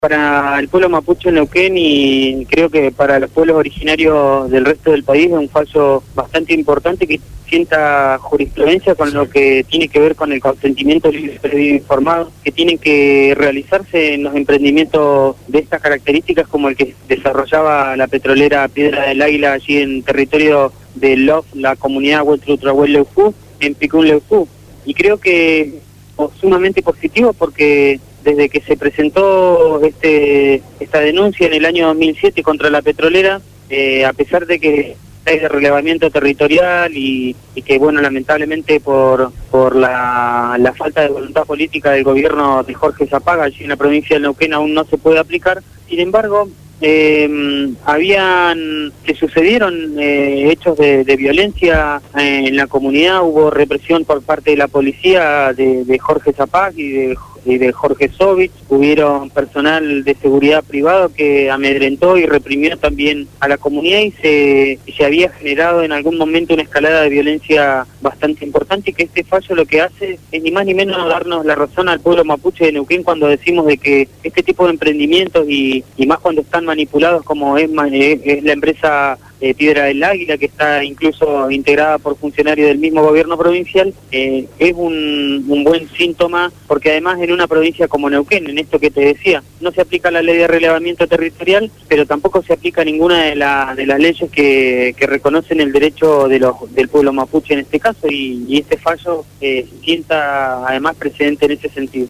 fue entrevistado en el programa “Abramos la boca” (Lunes a viernes 16 a 18 hs.) por Radio Gráfica.